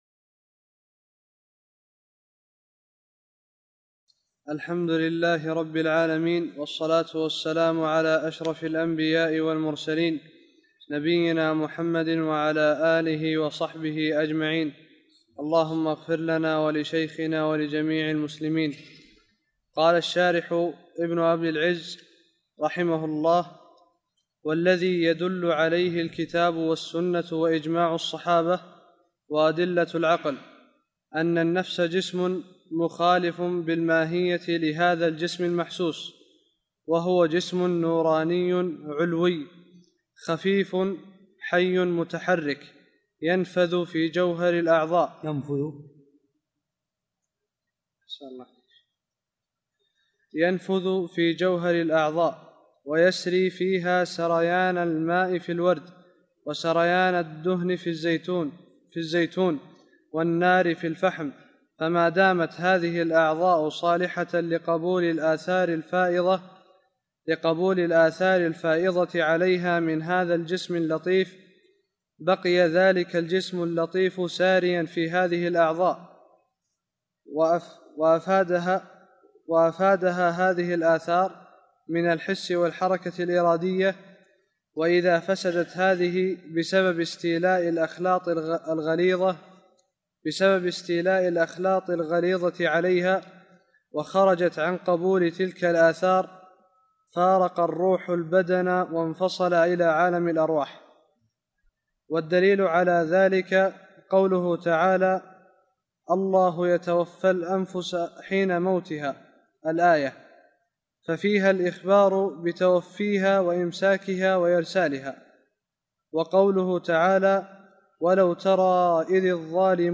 الدروس التعليق على شرح الطحاوية